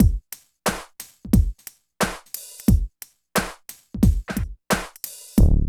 95 DRUM LP-L.wav